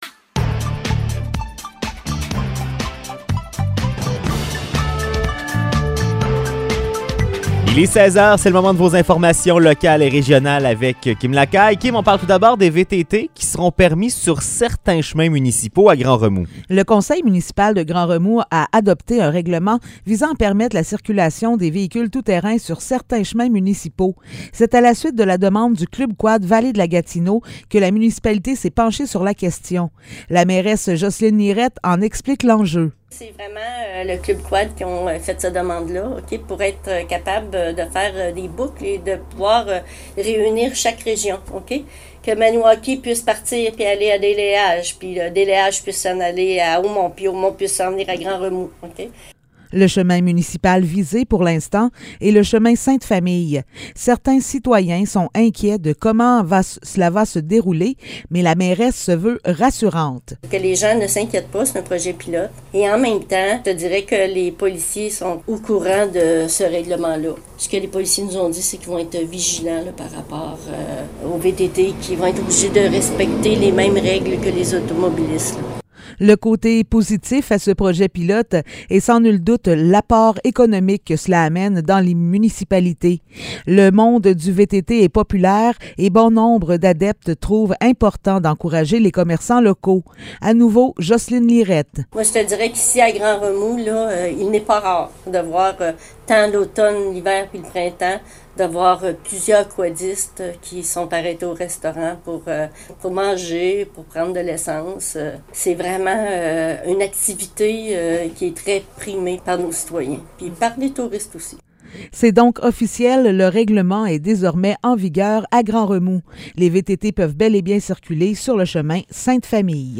Nouvelles locales - 3 août 2022 - 16 h